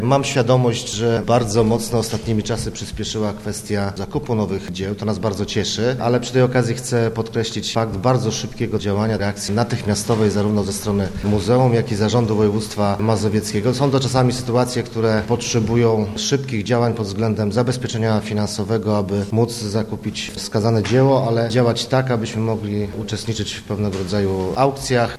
Podkreślił wagę współpracy Zarządu województwa z Muzeum: